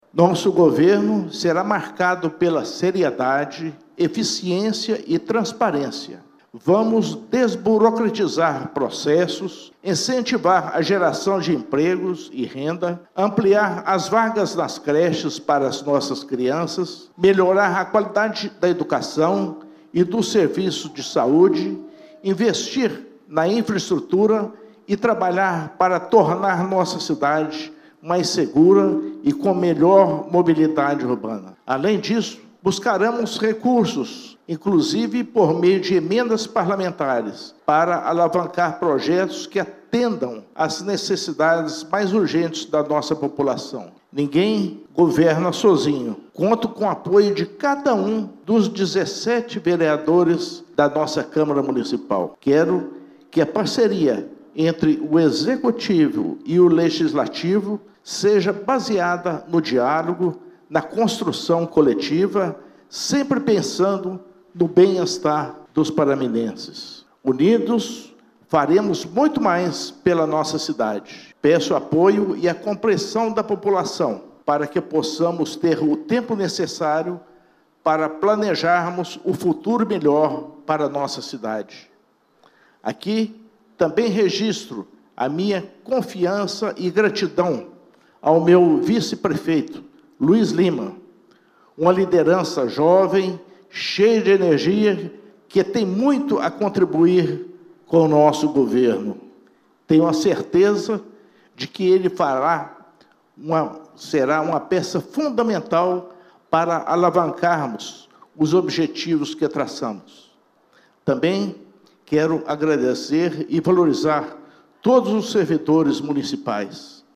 Inácio Franco e Luiz Fernando de Lima assumiram o comando do Município de Pará de Minas durante solenidade realizada no fim da tarde desta quarta-feira (1º), no Ápice Convenções e eventos, localizado no Bairro Providência, quando foram empossados vereadores para a legislatura 2025/2028, além do prefeito e vice-prefeito.